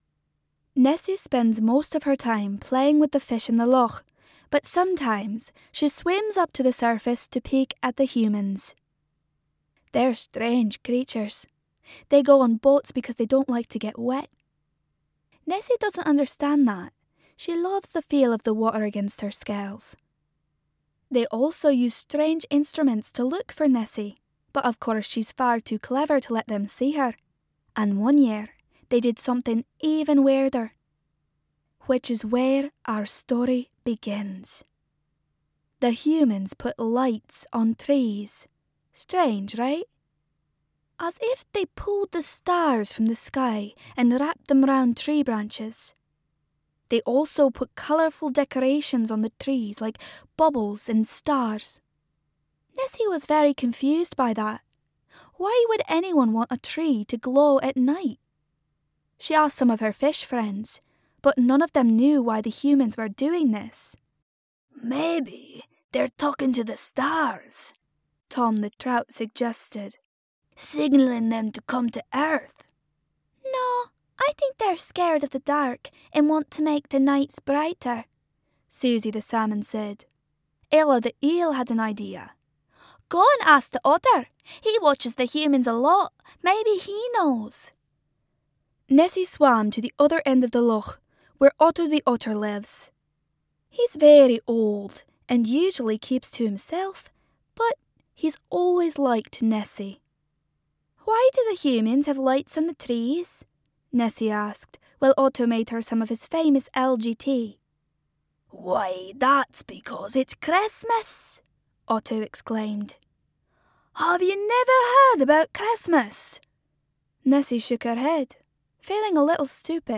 Teenager, Adult, Young Adult
Scottish